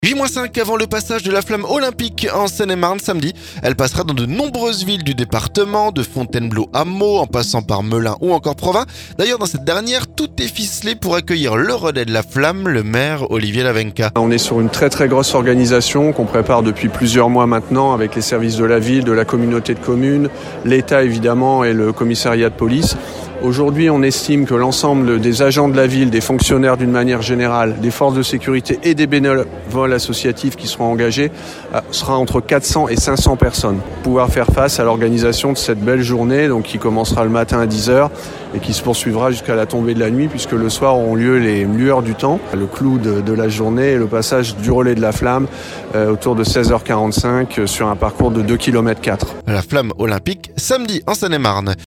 D'ailleurs dans cette dernière, tout est ficelé pour accueillir le relais de la flamme. Le maire, Olivier Lavenka.